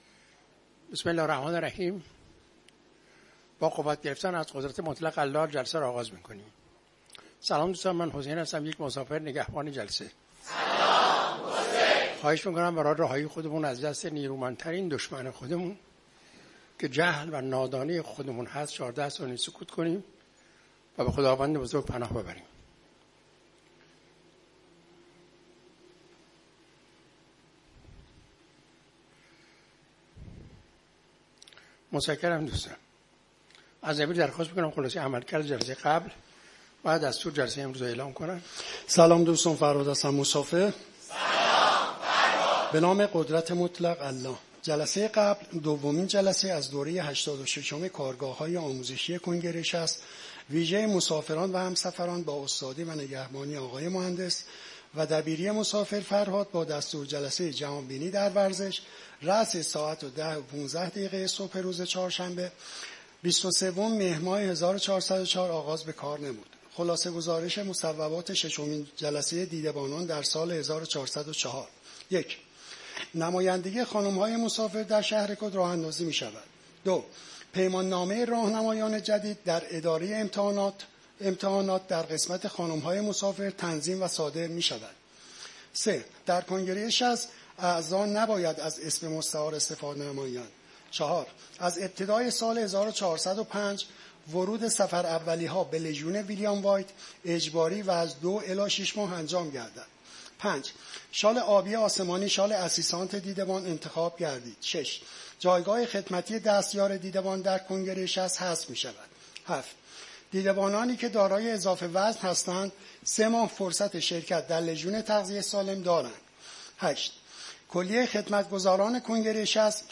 کارگاه آموزشی جهان‌بینی؛ DST ، OT